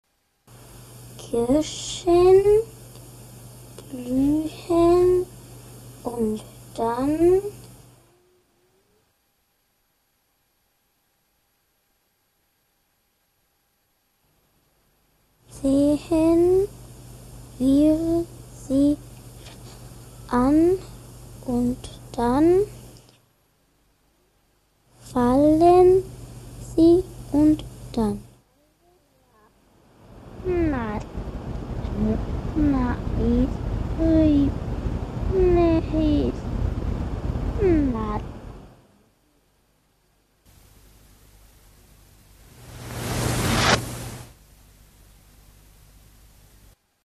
Klanginstallationen - Ausschnitte im mp3-Format